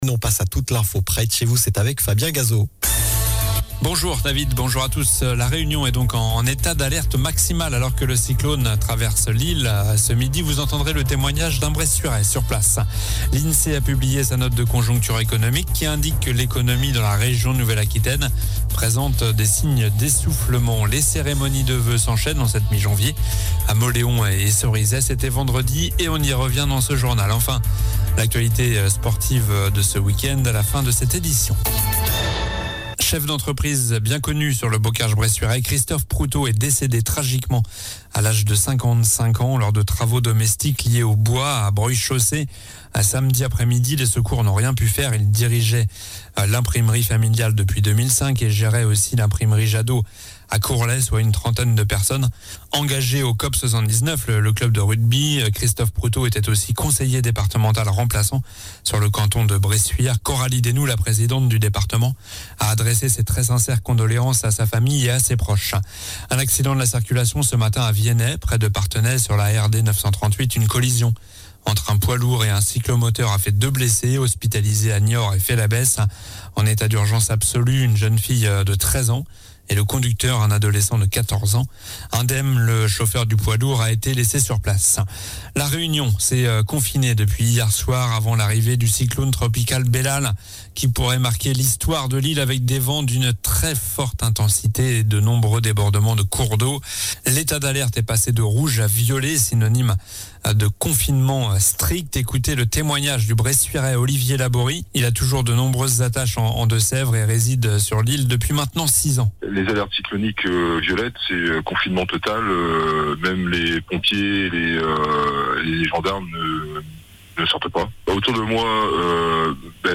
Journal du lundi 15 janvier (midi)